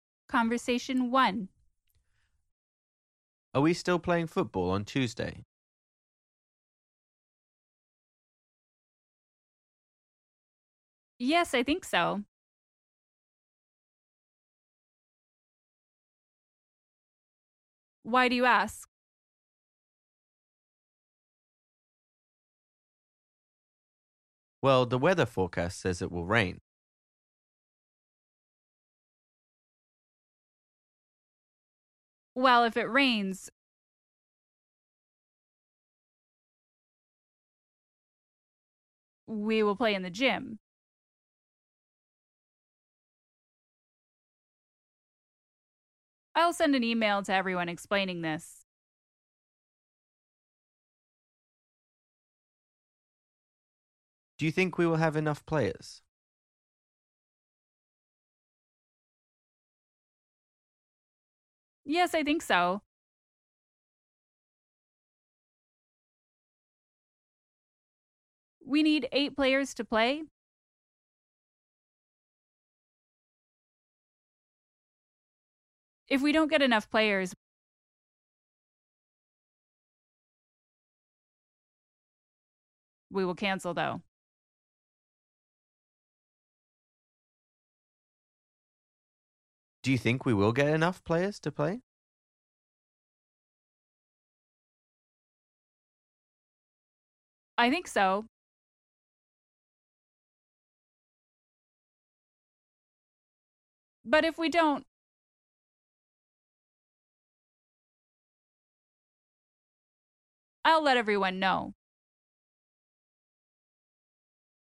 TRAIN - Conversation 1 - Are we still playing?